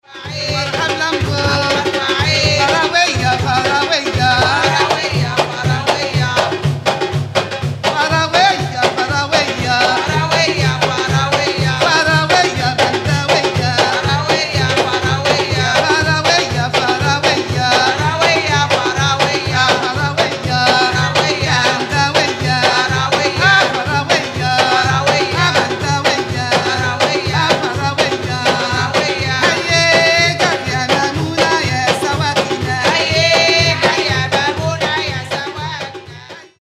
Zar music is raw and unpolished.